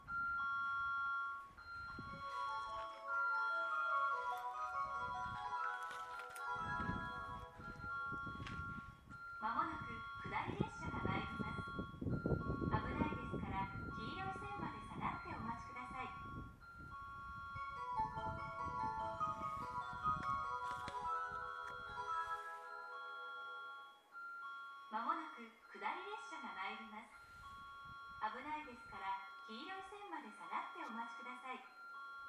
この駅では接近放送が設置されています。
２番線奥羽本線
接近放送普通　青森行き接近放送です。